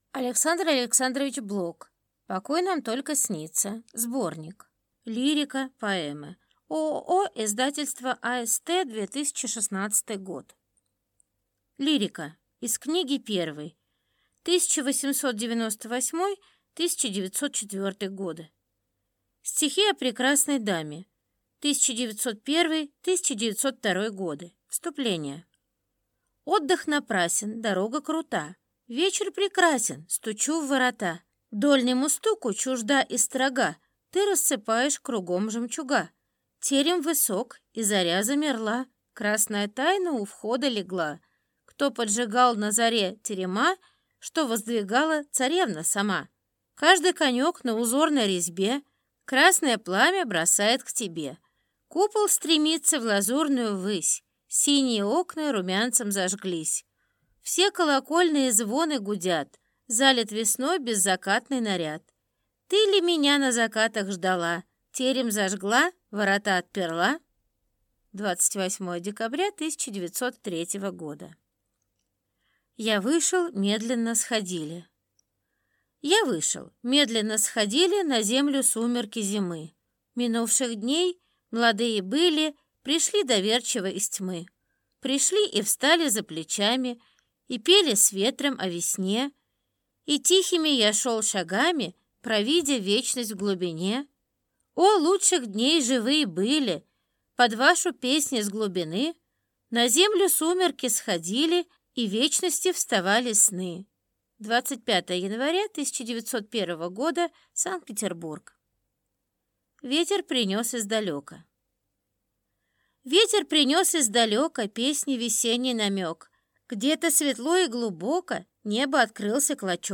Аудиокнига Покой нам только снится (сборник) | Библиотека аудиокниг